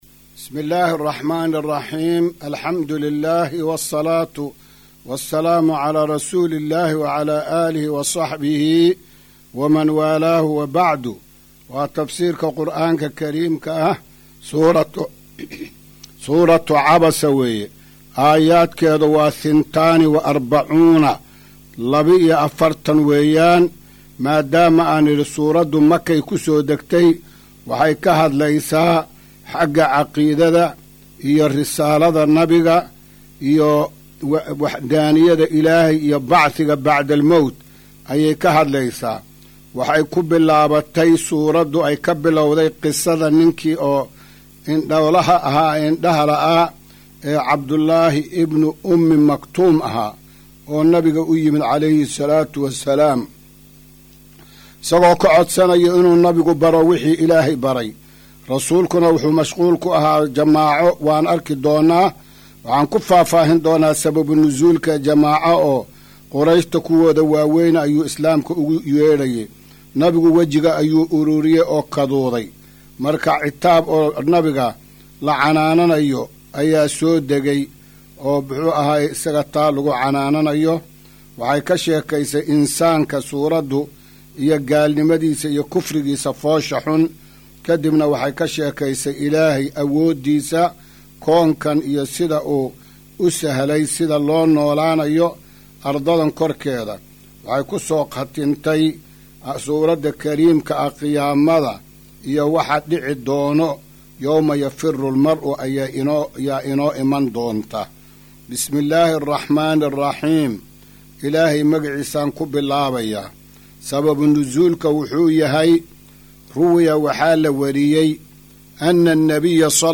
Maqal:- Casharka Tafsiirka Qur’aanka Idaacadda Himilo “Darsiga 280aad”